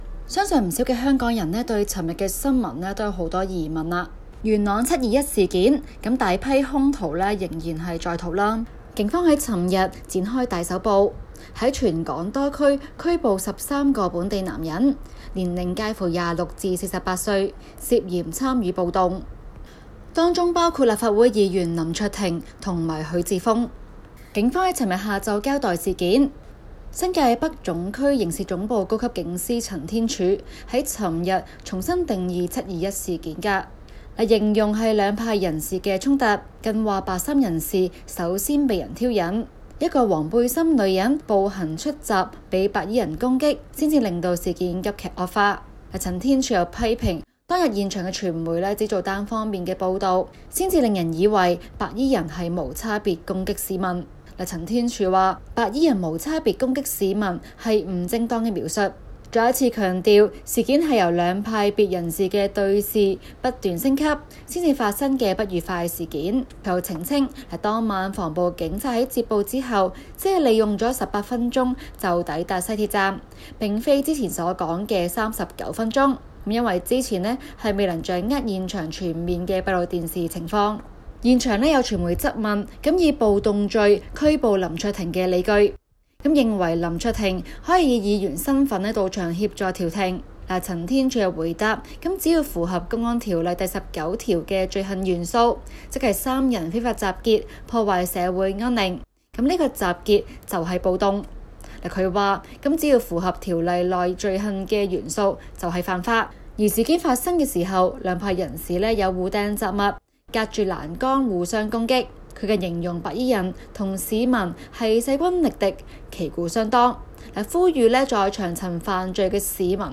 今期 【中港快訊 】環節報道警方拘捕兩名立法會議員涉721元朗暴動罪。